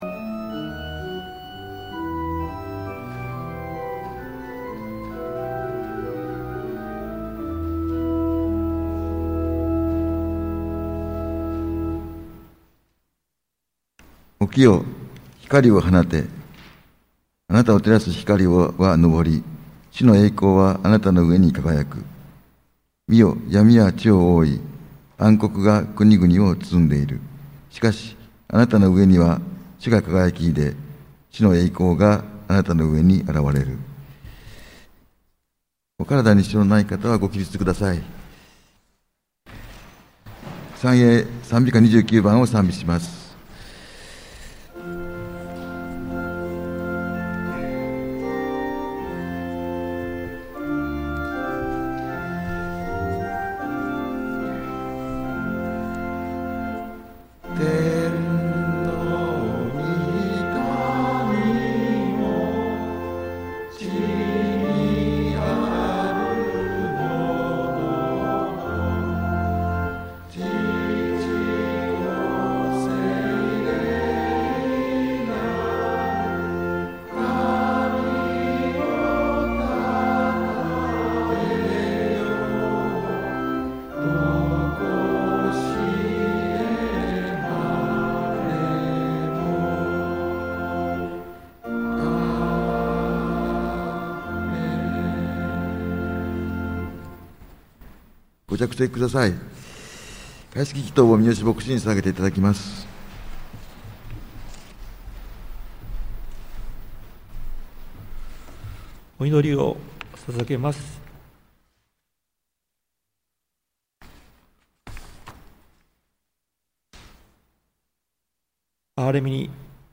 2024年12月29日 日曜礼拝（音声）